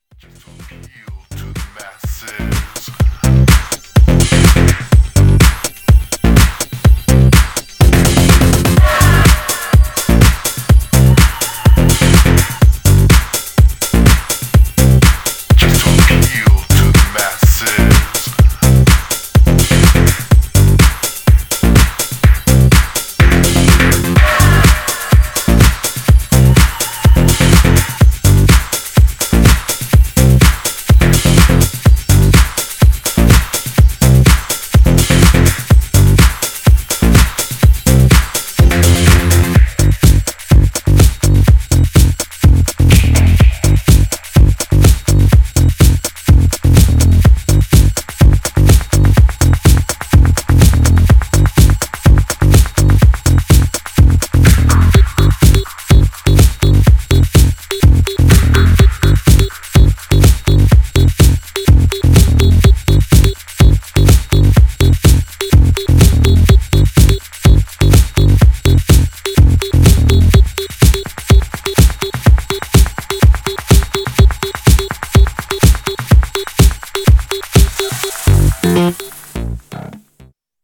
Styl: Electro, House, Techno, Breaks/Breakbeat